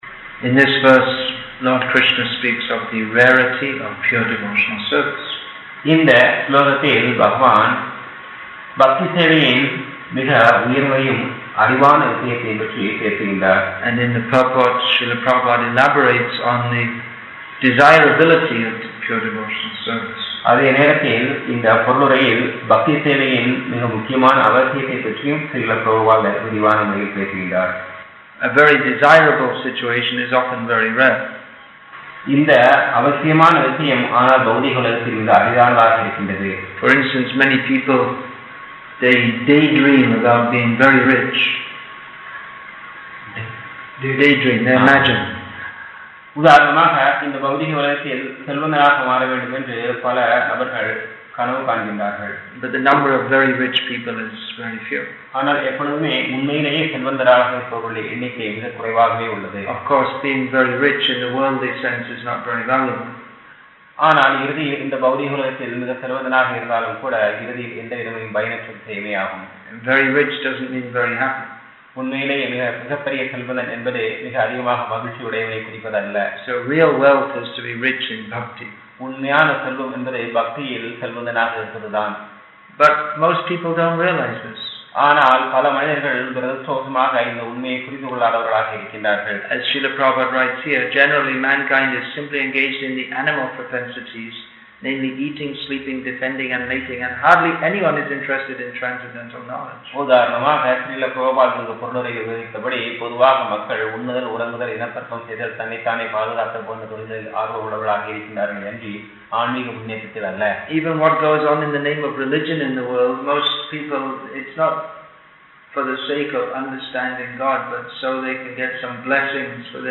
English with தமிழ் (Tamil) Translation; Vellore, Tamil Nadu , India